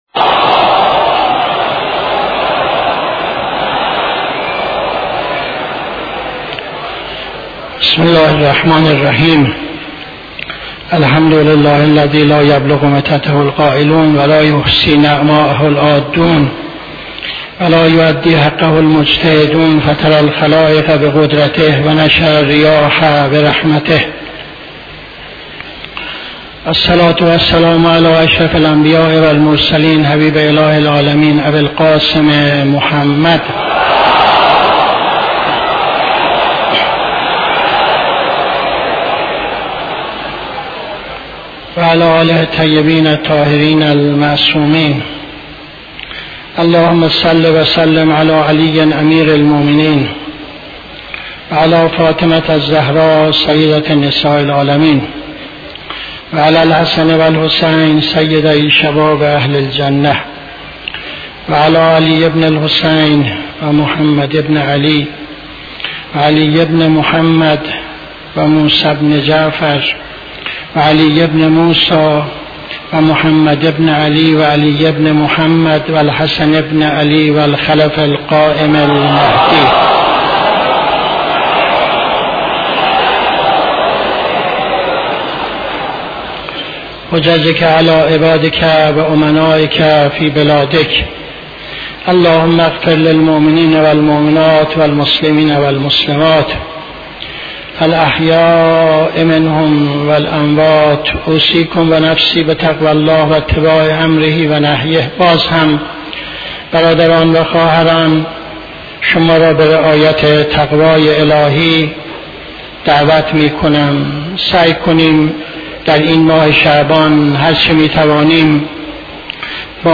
خطبه دوم نماز جمعه 20-09-77